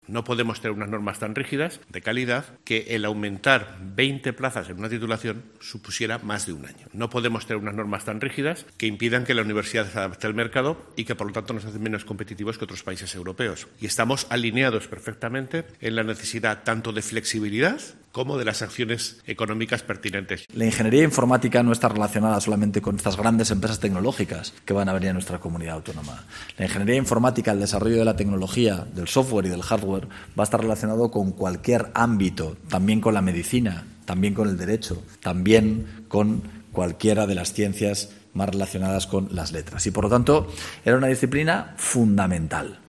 El rector y el presidente del Gobierno, sobre la creación de nuevas plazas en Ingeniería Informática